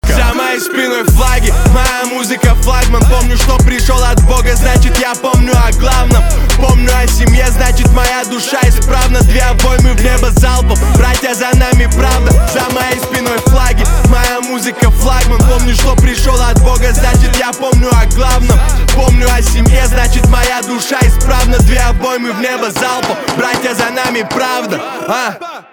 русский рэп , битовые , басы , пацанские , качающие